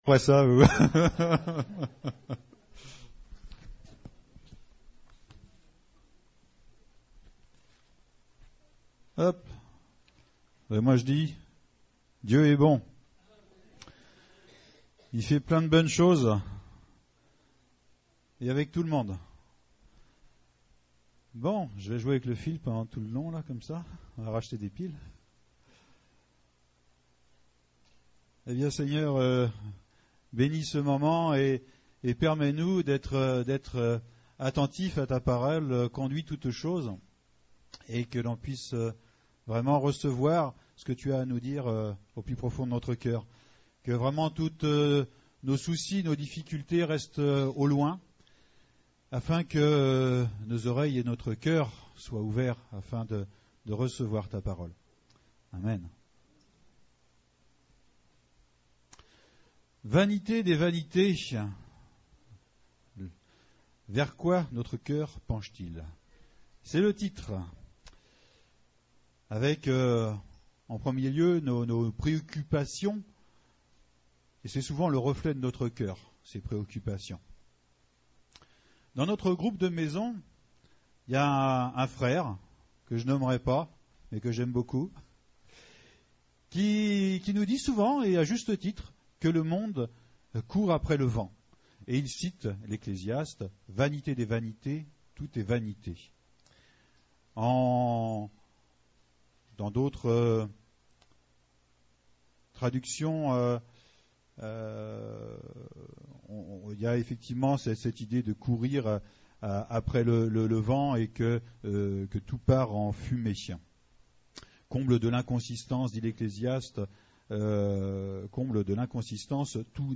Ecclésiaste 1:2 Type De Service: Culte Thèmes: Vie chrétienne personnelle « La foi comme un grain de moutarde Le Tabernacle de David